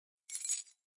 钥匙扣 " 钥匙扣13
描述：录音设备：Sony PCMM10Format：24 bit / 44.1 KHz
Tag: 样品 记录 弗利